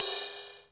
HR16B  RIDE.wav